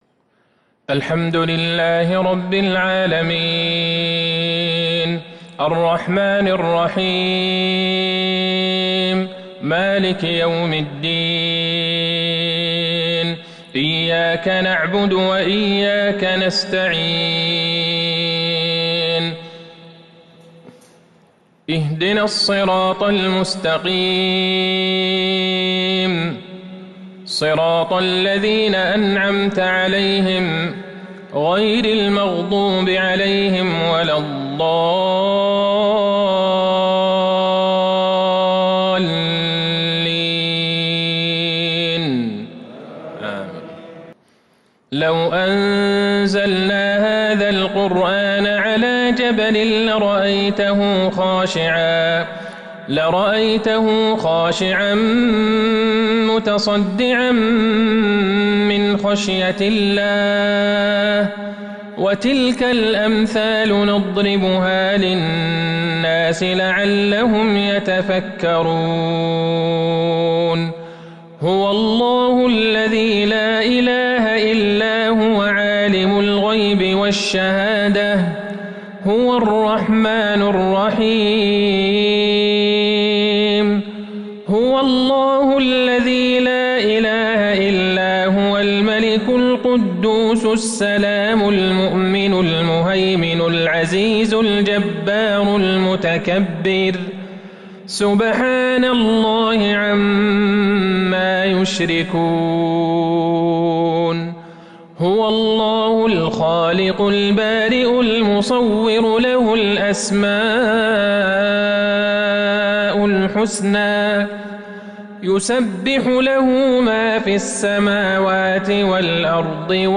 صلاة المغرب السبت 10/1/1442 أواخر سورتي الحشر والانفطار salat almaghrib 29/8/2020 suras Alhashr And Alenfitar > 1442 🕌 > الفروض - تلاوات الحرمين